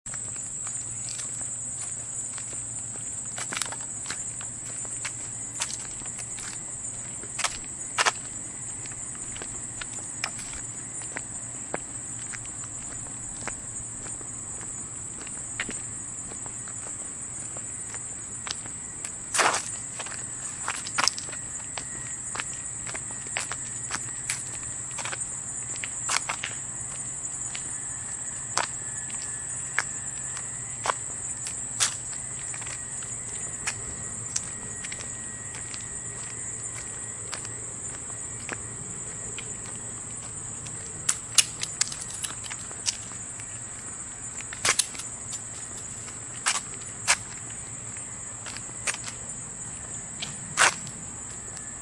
描述：户外录制的蟋蟀鸣叫声，之后有脚步声。
Tag: 氛围 板球 现场记录 脚步